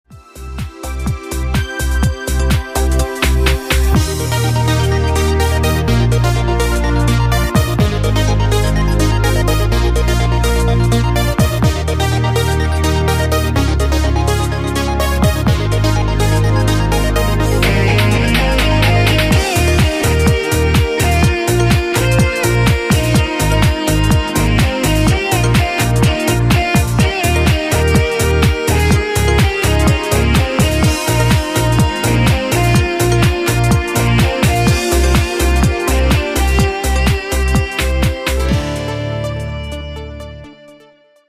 Il ritmo è ricco di variazioni sempre armoniose.